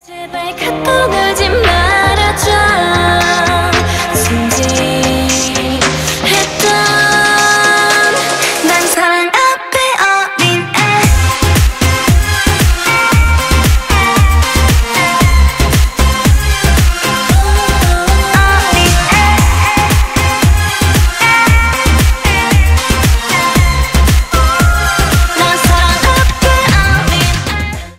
Поп Музыка # Корейские